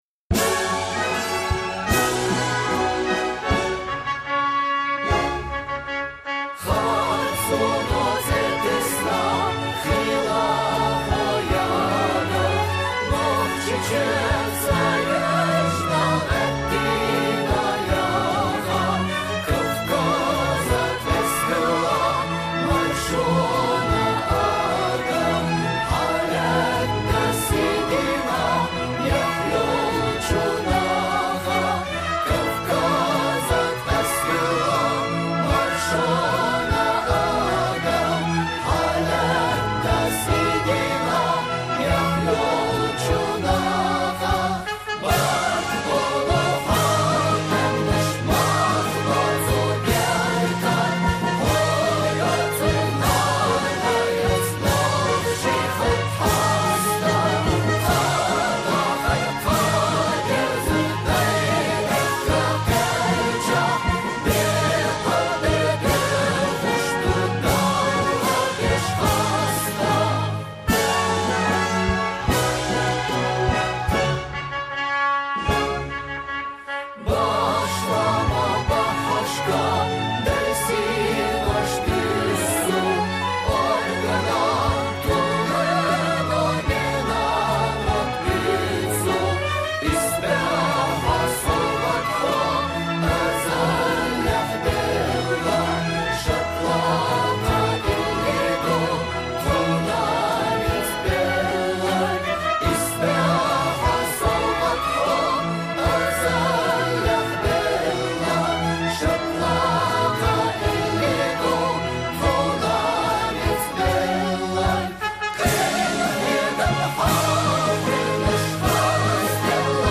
Национальный гимн Чечни со словами